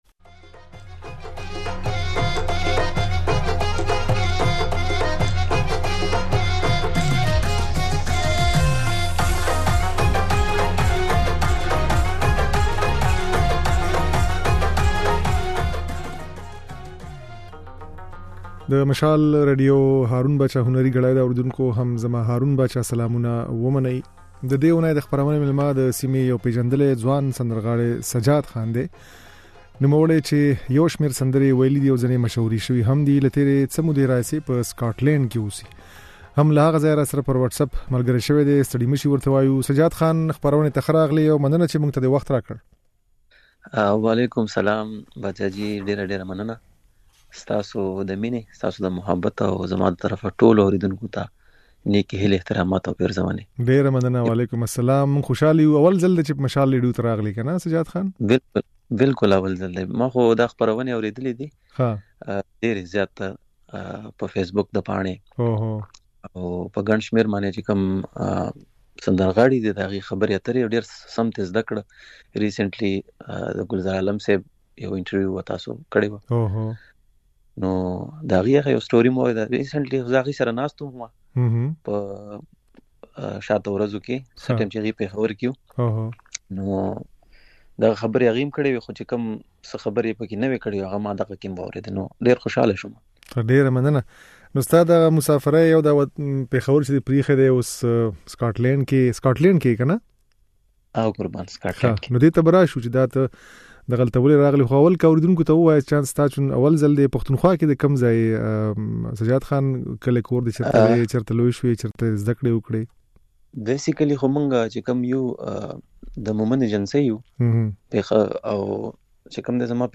د نوموړي خبرې او ځينې سندرې يې په خپرونه کې اورېدای شئ.